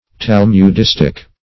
Talmudistic \Tal`mud*is"tic\